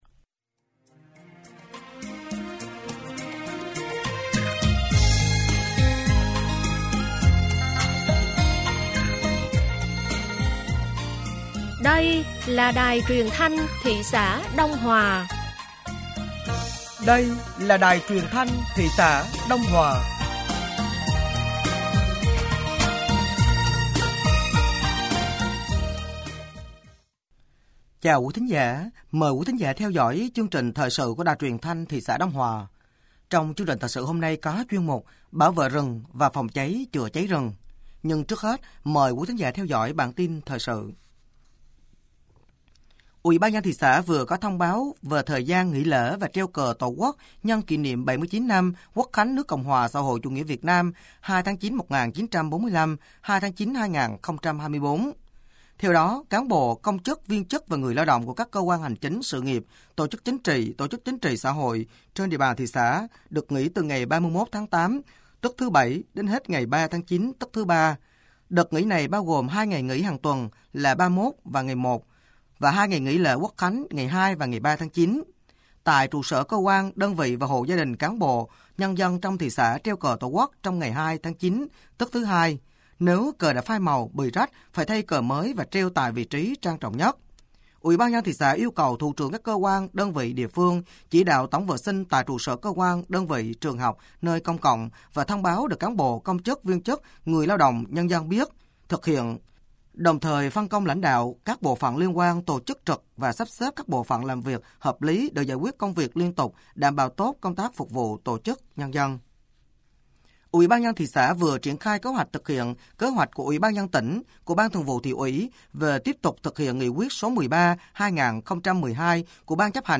Thời sự tối ngày 24 và sáng ngày 25 tháng 8 năm 2024